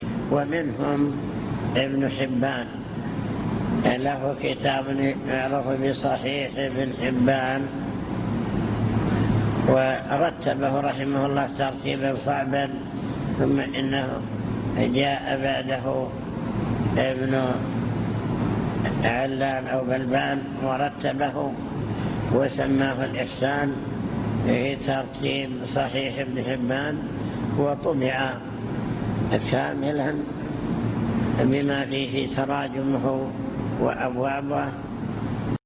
المكتبة الصوتية  تسجيلات - محاضرات ودروس  محاضرات بعنوان: عناية السلف بالحديث الشريف من جاء بعد أصحاب الكتب الستة